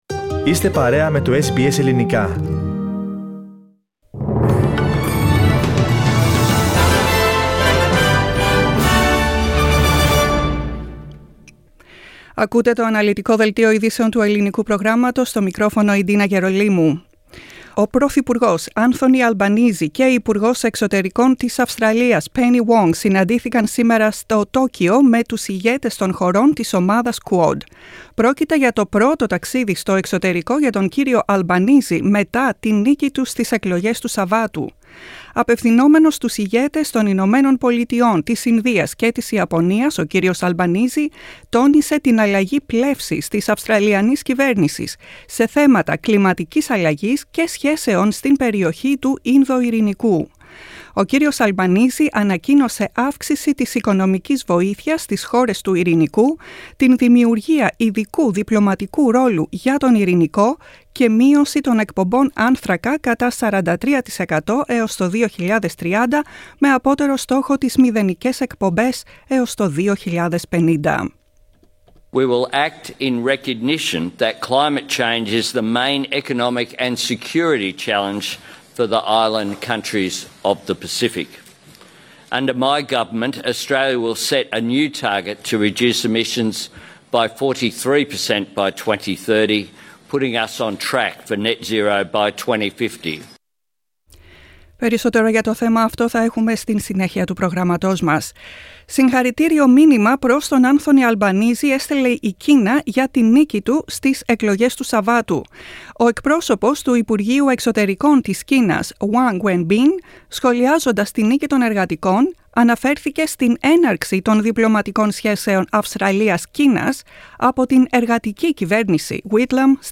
Δελτίο ειδήσεων, 24.05.22
Ακούστε το κεντρικό δελτίο ειδήσεων του Ελληνικού Προγράμματος σήμερα Τρίτη 24 Μαίου 2022.